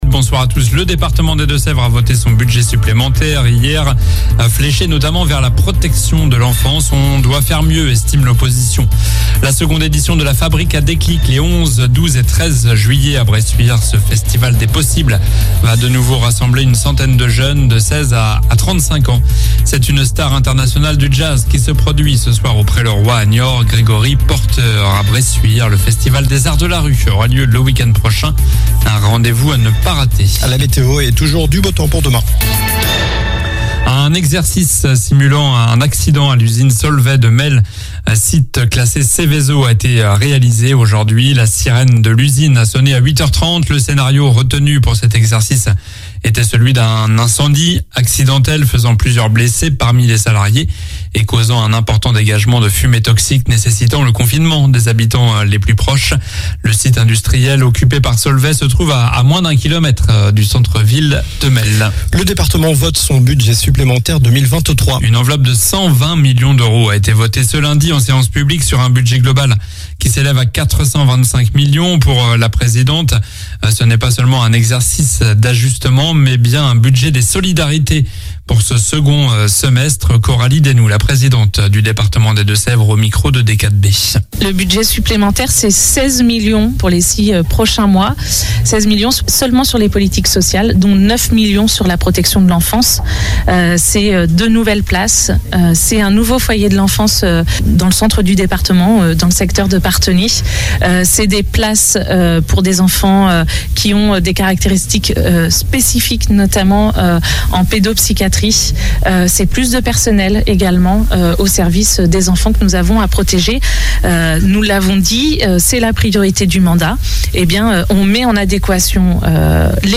Journal du mardi 27 juin (soir)